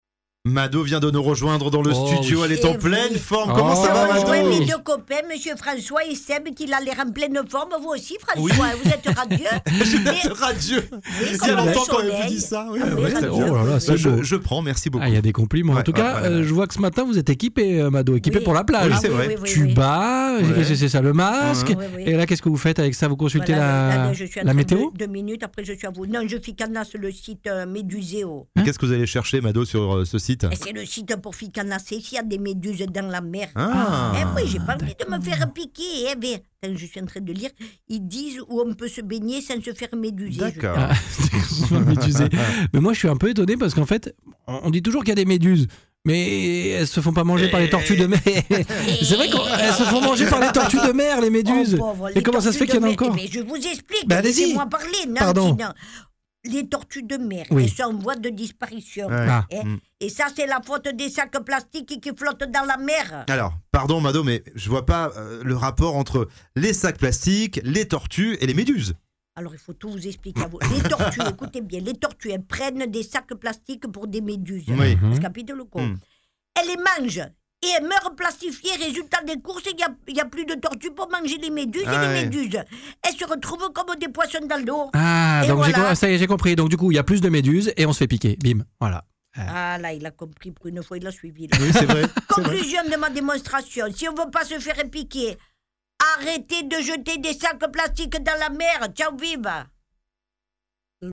Mado La Niçoise est une humoriste française.
Elle est connue pour son personnage de Mado La Niçoise, une femme originaire de Nice qui parle avec l'accent niçois et qui aborde des sujets de la vie quotidienne avec humour et ironie.